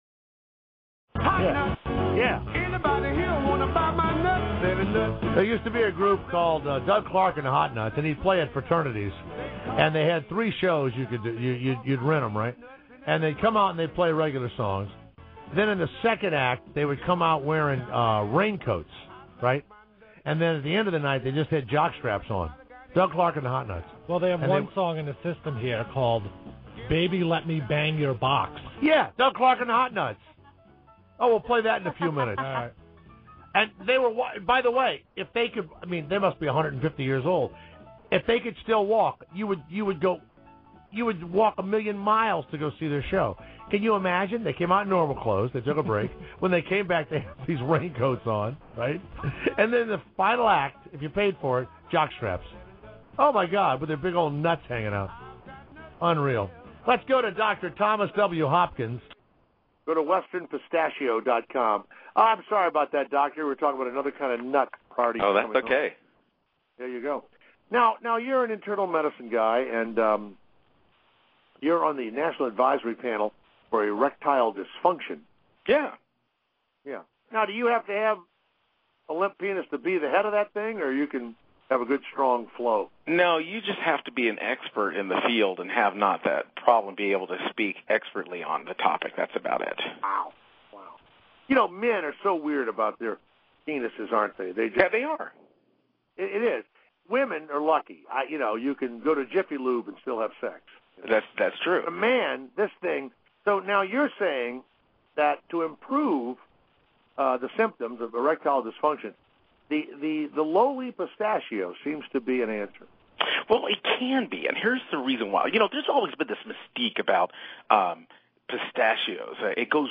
Radio interviews:
1:00 pm Pacific Time/4:00 pm Eastern Time: ten minute live interview on Jay Thomas Show (XM/Sirius Radio/Nationally syndicated).